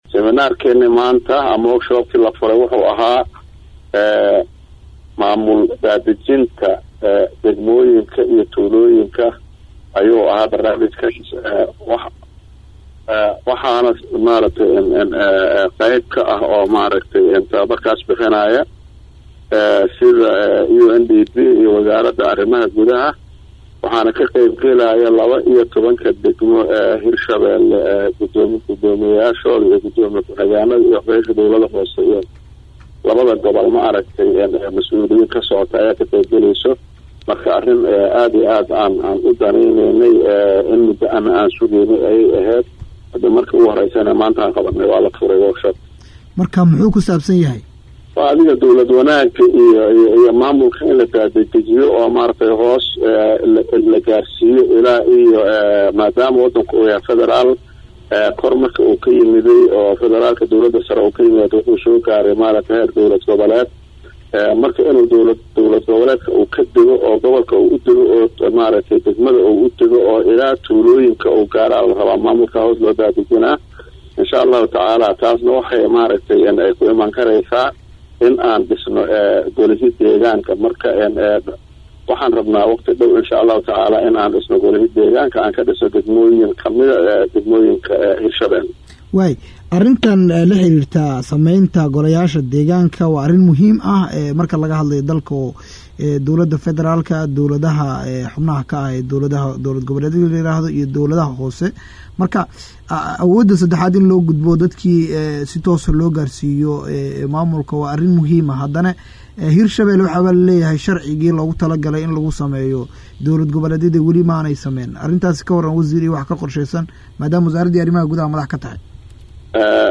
Wasiirka Arrimaha gudaha ee dowlad goboleedka Hirshabeelle Maxamed Cali Caadle oo la hadlay Radio Muqdisho ayaa sheegay in sameynta golayaasha deegaanka ay tahay mid muhiim ah, ayna qorsheynayaan inay sii joogteeyaan siminaarada looga hadlayo dowlad wanaagga iyo dhismaha dowladaha Hoose.
Waraysi-Wasiirka-Arimaha-Gudaha-ee-Hirshabeelle-Maxamed-Cali-caadle.mp3